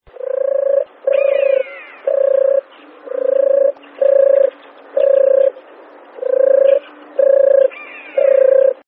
Turkawka - Streptopelia turtur